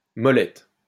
Molette (French pronunciation: [mɔlɛt]